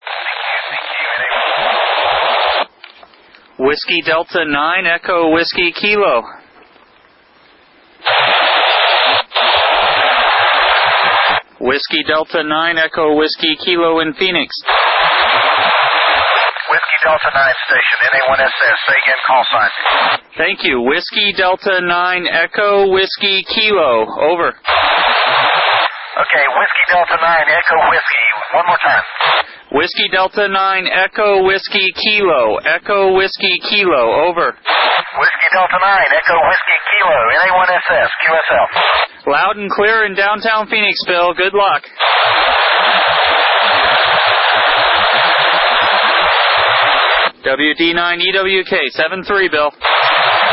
voice contact with Bill McArthur at NA1SS, using an Icom IC-T7H HT at 5W with an Arrow Antennas handheld 2m/70cm Yagi.
standing in downtown Phoenix (grid DM33xl) for this contact.